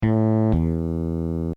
Game_over
game game-over over synth two-beat wav sound effect free sound royalty free Music